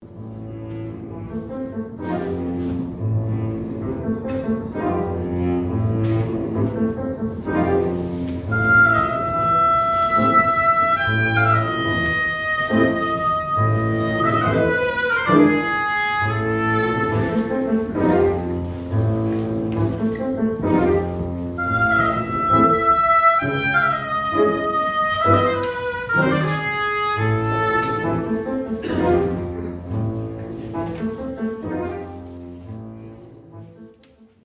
Oboe, Oboe d’Amore und dem Englischhorn